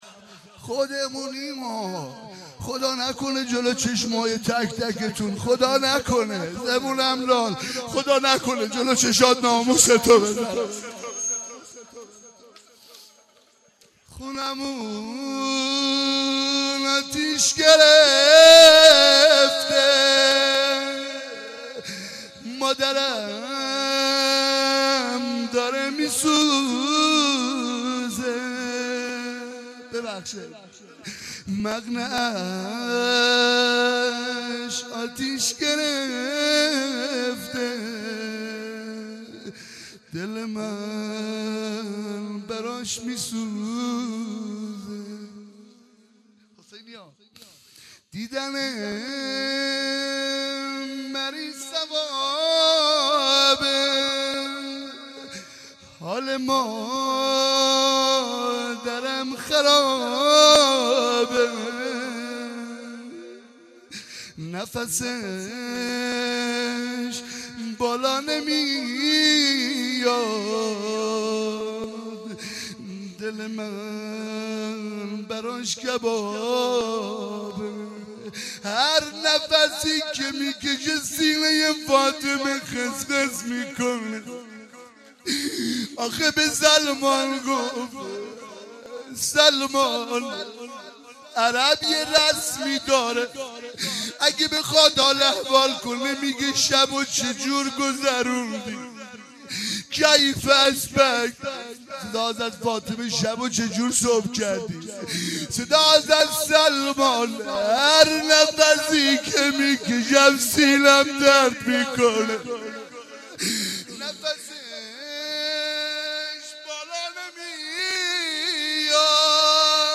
دانلود مداحی چادرش سوخته - دانلود ریمیکس و آهنگ جدید